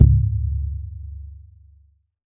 Index of /musicradar/essential-drumkit-samples/Hand Drums Kit
Hand Big CowHide Drum.wav